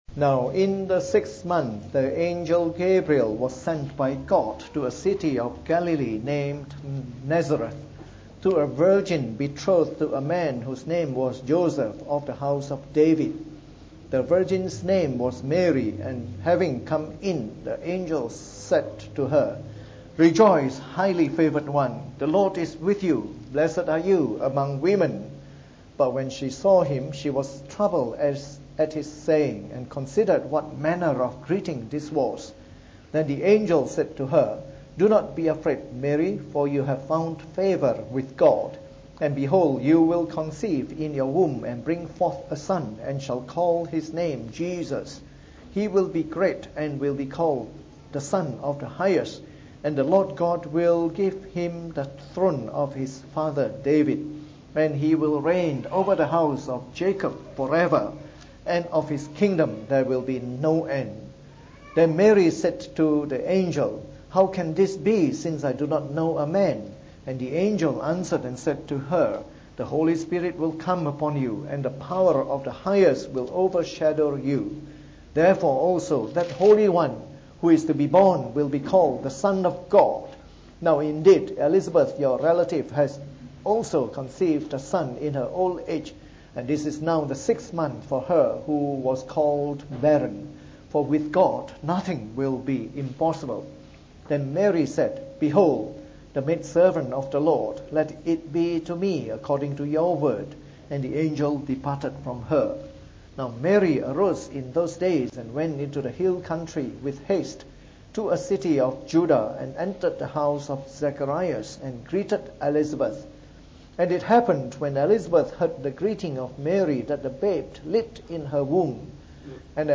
From our new series on the “Gospel According to Luke” delivered in the Evening Service.